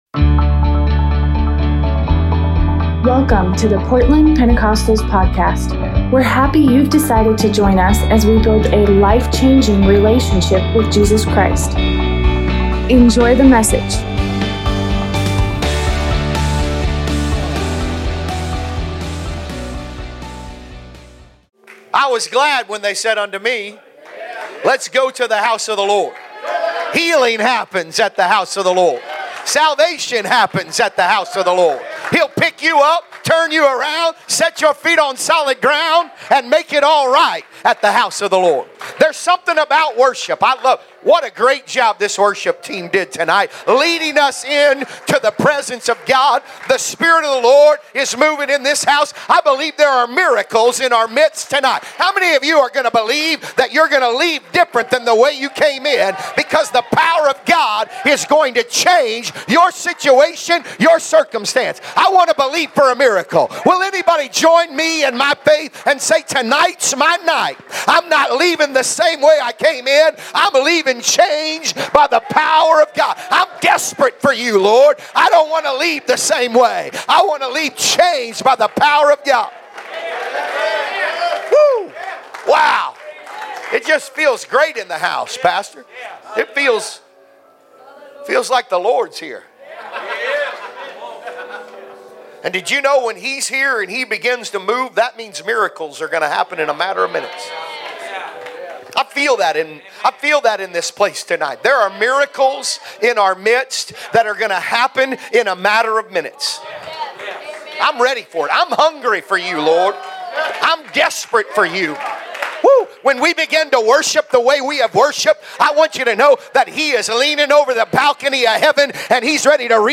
Tuesday night sermon